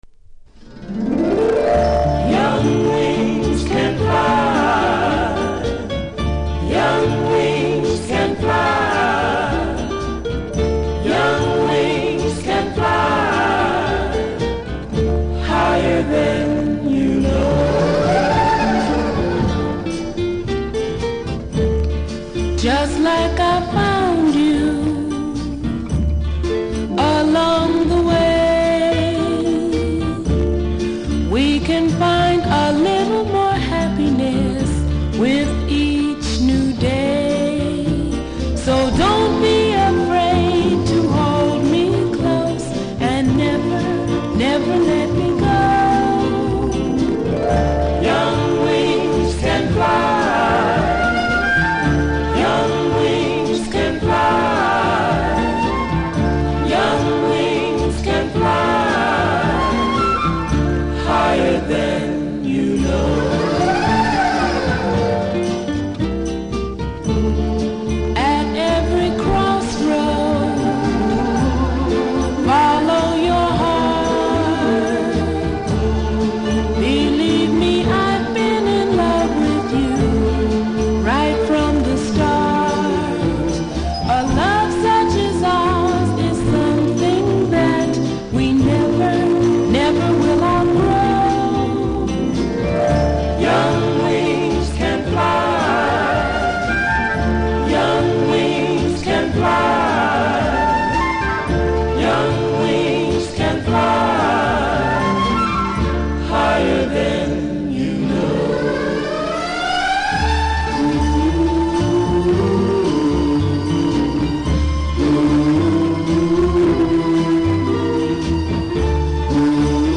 録音レベルが高いのか少し音割れ見たいに感じますので試聴で確認下さい（その分値段下げての出品です）